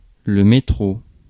Ääntäminen
UK : IPA : /ˈsʌb.ˌweɪ/ US : IPA : /ˈsʌb.ˌweɪ/